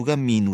u camminu [ ɡ ] : sonore